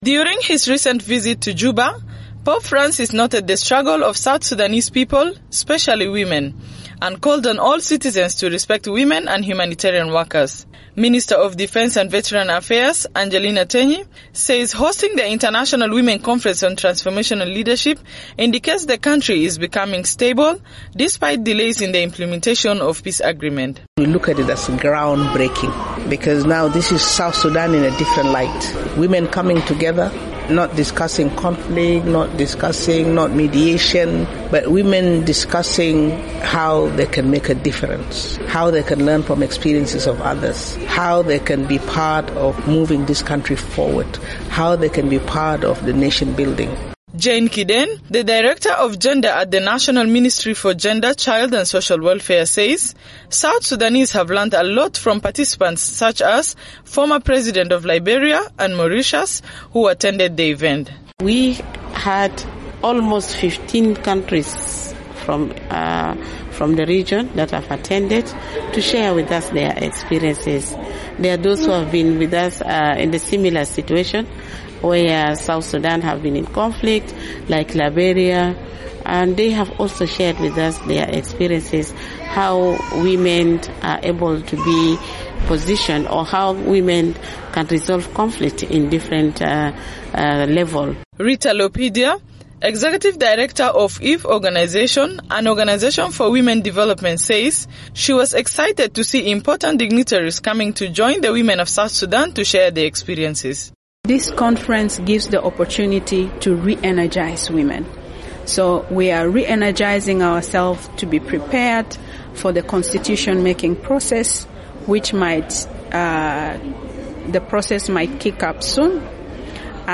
An international women’s conference on transformational leadership in South Sudan wrapped up Wednesday, described by many participants as timely following Pope Francis’ recent visit. South Sudan’s Defense Minister Angelina Teny says the conference is "groundbreaking" for South Sudanese women.